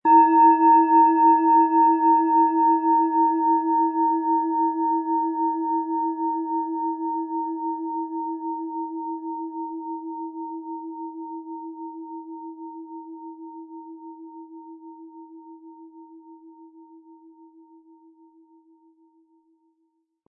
Hopi Herzton
Mit Klöppel, den Sie umsonst erhalten, er lässt die Planeten-Klangschale Hopi-Herzton voll und harmonisch erklingen.
MaterialBronze